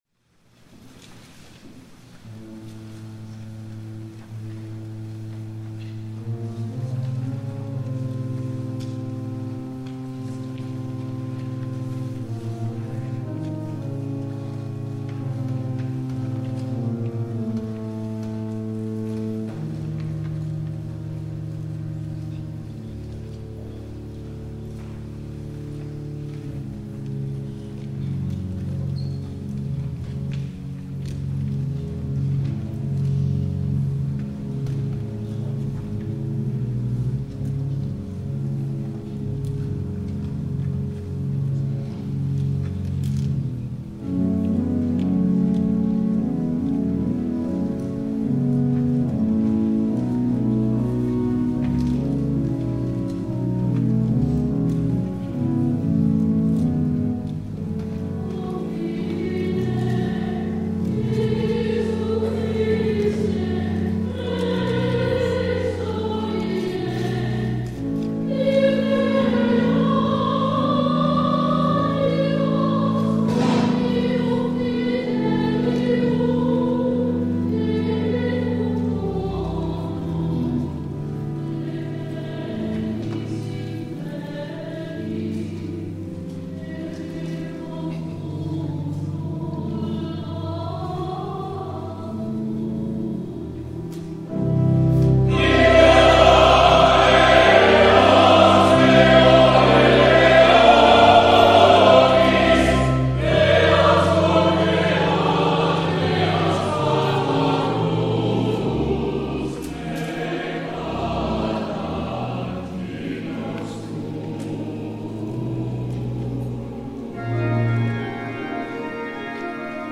THE CHORAL RESPONSE